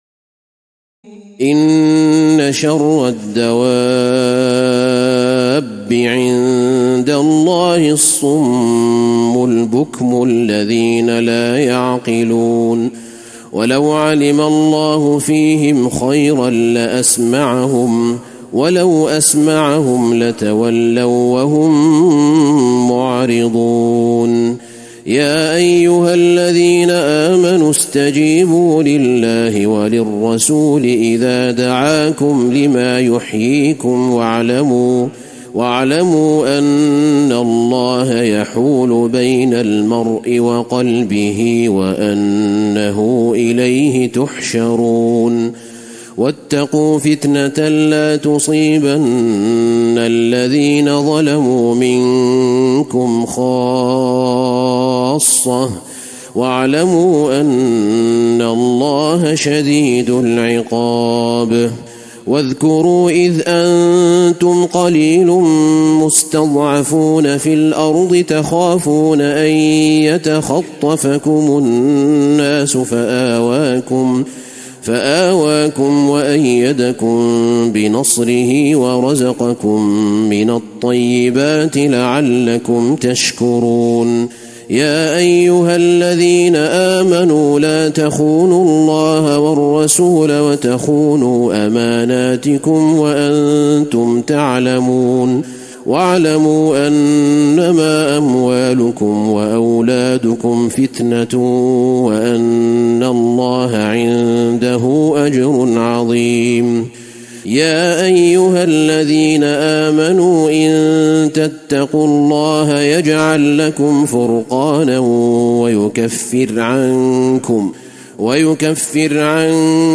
تراويح الليلة التاسعة رمضان 1435هـ من سورتي الأنفال (22-75) و التوبة (1-33) Taraweeh 9 st night Ramadan 1435H from Surah Al-Anfal and At-Tawba > تراويح الحرم النبوي عام 1435 🕌 > التراويح - تلاوات الحرمين